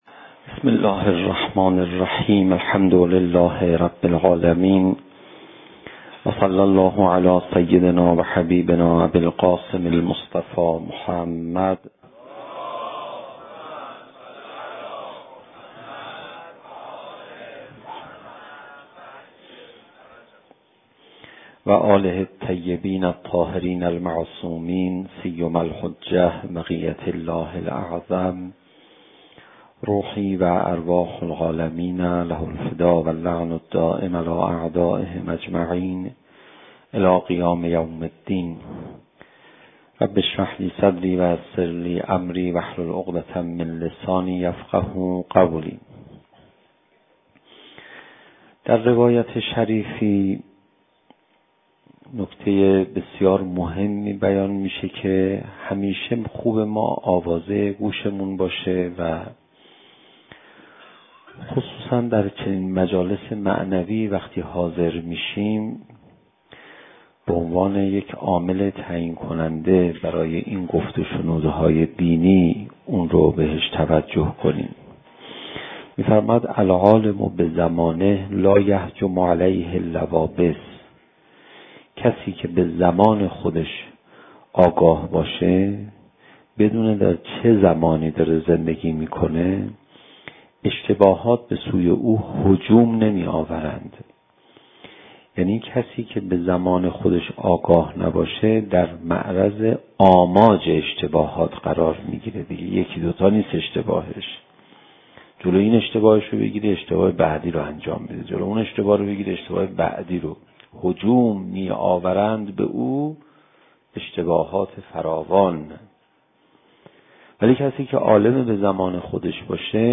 زمان: 46:43 | حجم: 10.9 MB | تاریخ: 1393 | مکان: حسینیة آیت الله حق شناس